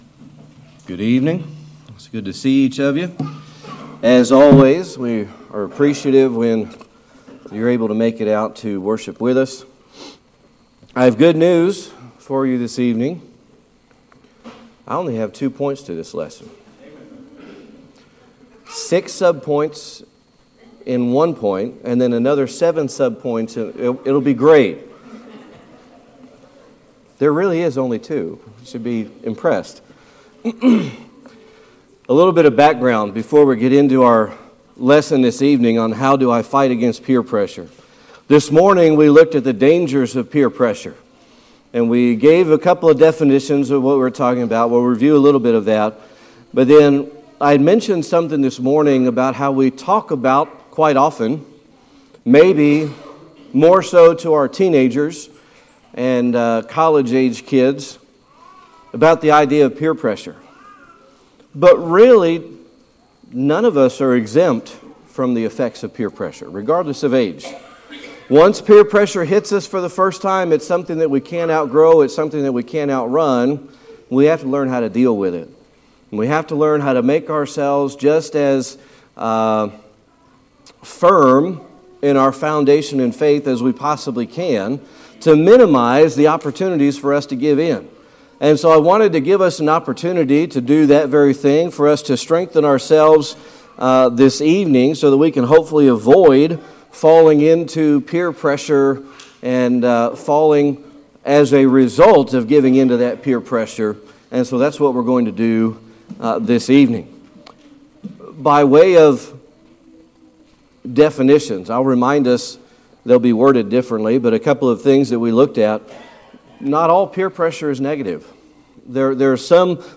Psalm 119:11 Service Type: Sunday Evening Worship Before we get into our lesson on how to fight against peer pressure